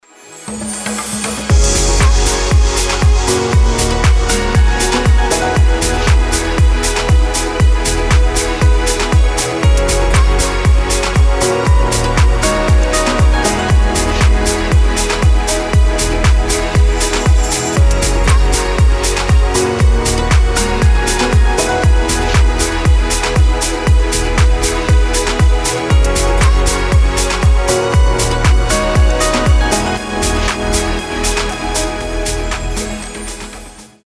красивые
мелодичные
этнические
Melodic house
organic house